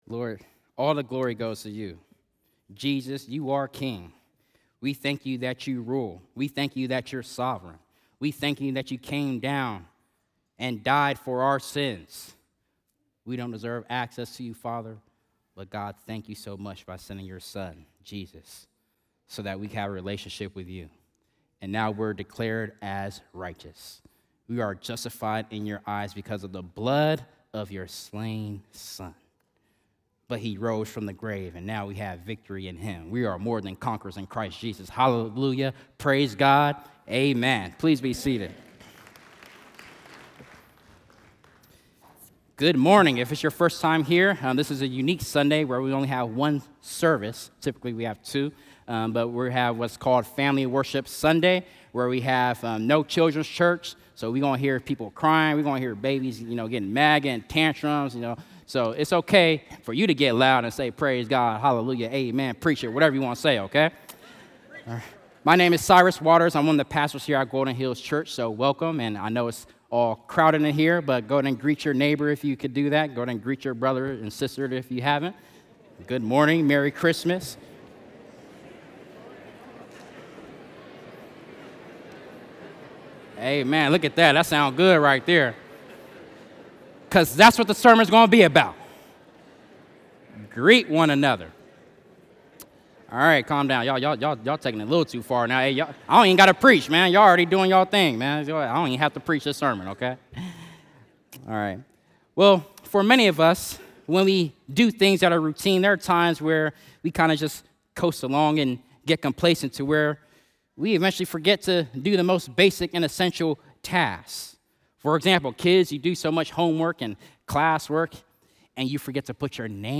Family Worship Sunday, Last Sunday of 2025; Philippians 4:21–23